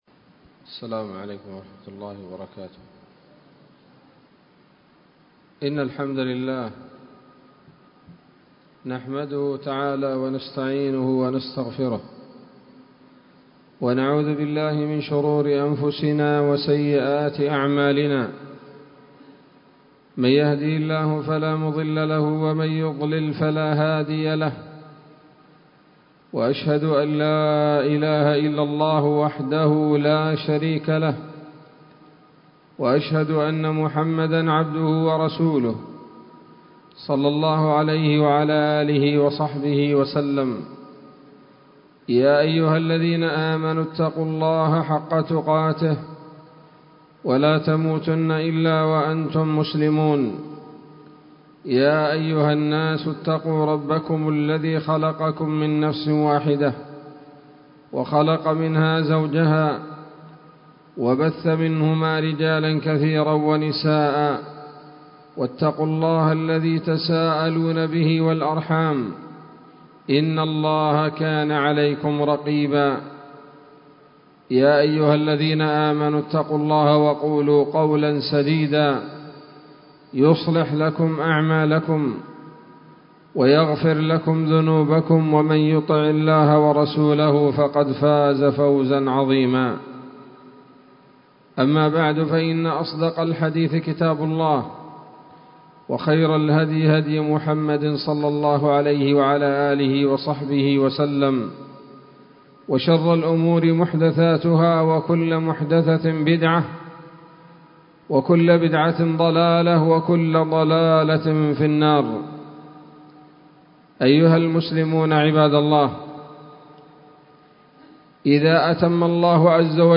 خطبة جمعة بعنوان: (( وداعا شهر الخيرات )) 28 رمضان 1443 هـ، دار الحديث السلفية بصلاح الدين